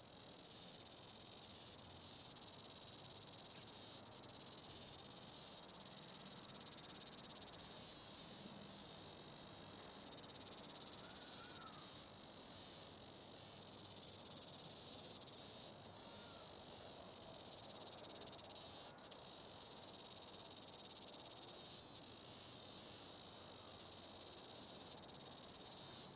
cicadasLoop_1.wav